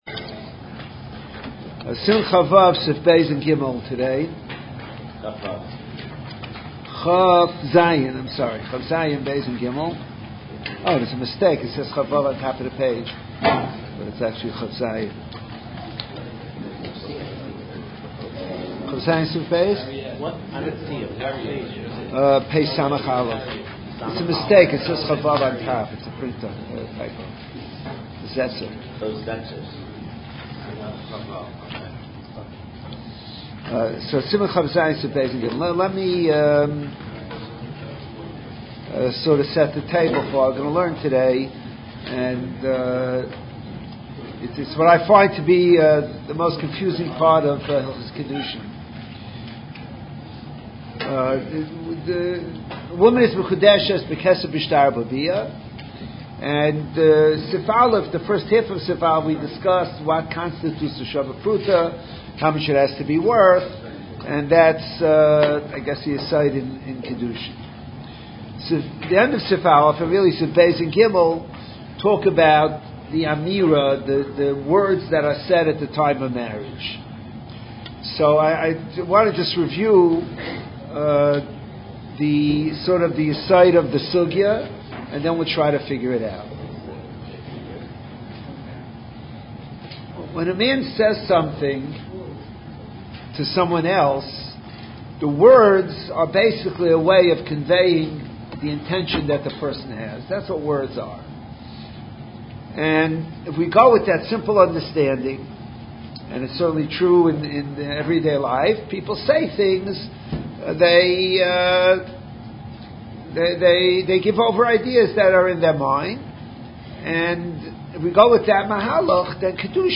Shiurim and speeches on Gemarah, Halachah, Hashkofo and other topics, in mp3 format